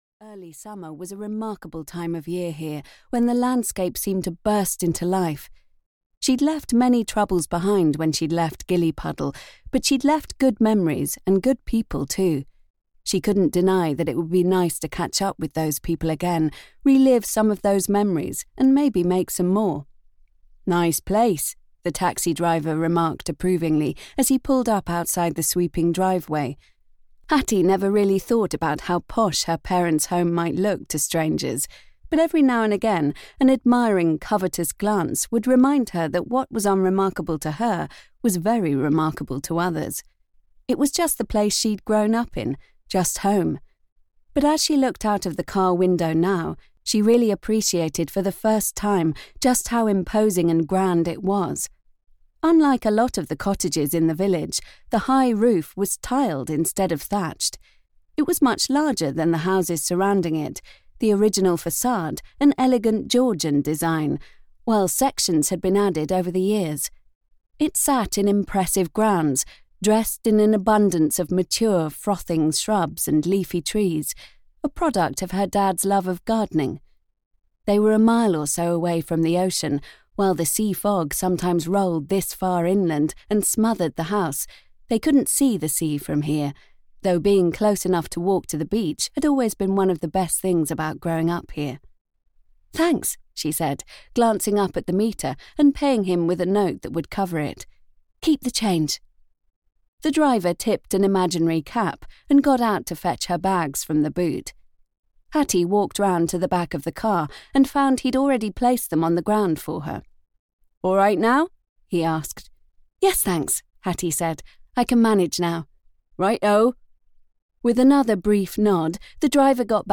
Hattie's Home for Broken Hearts (EN) audiokniha
Ukázka z knihy